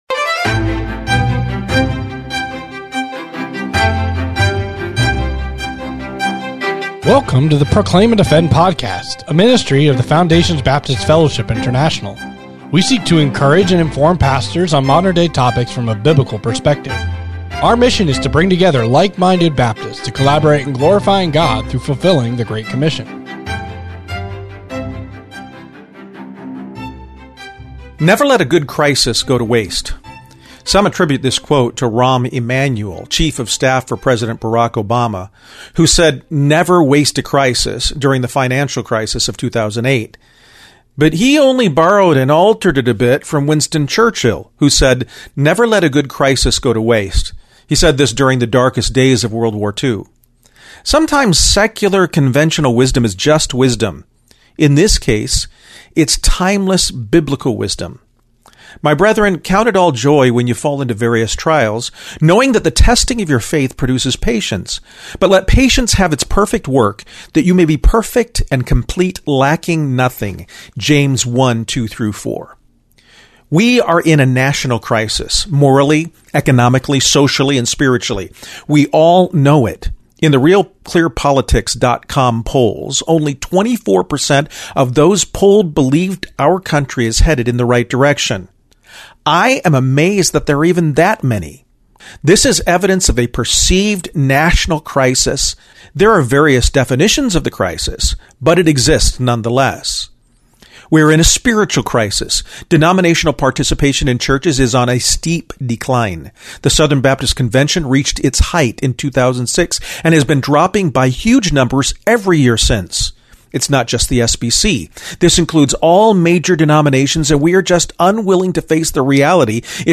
Audio Version of this article.